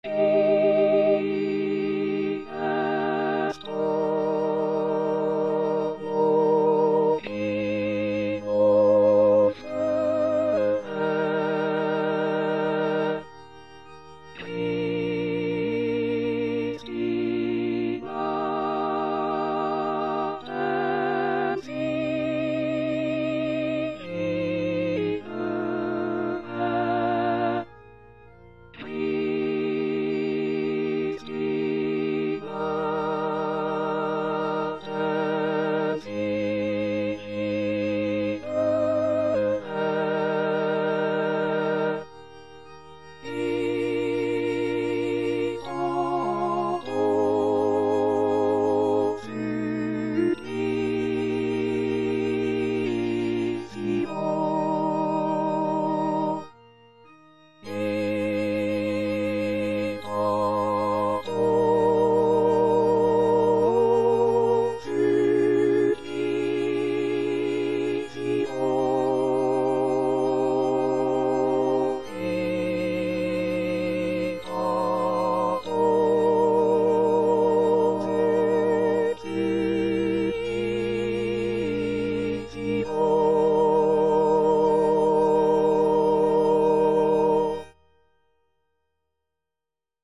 Parole 3: Mulier, ecce filius tuus        Prononciation gallicane (à la française)